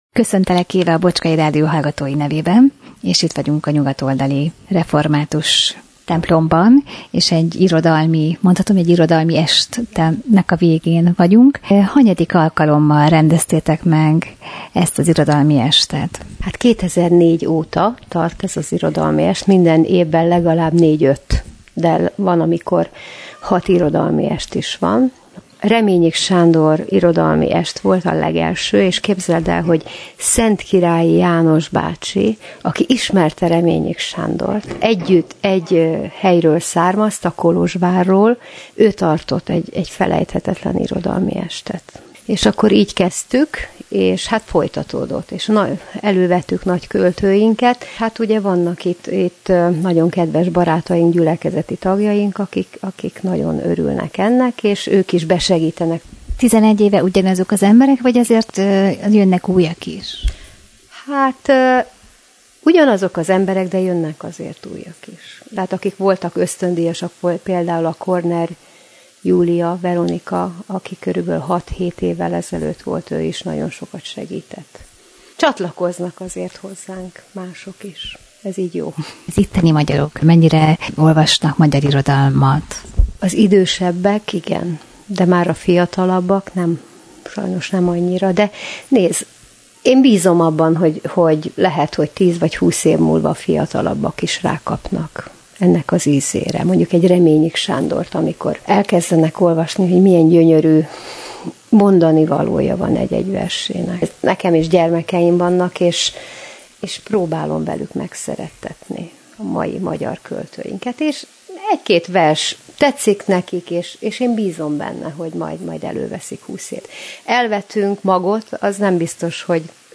A Nyugat- oldali evangélikus egyház november 21-én irodalmi estet rendezett a Nyugat -oldali református egyház kis termében.
irodalmiestjav.mp3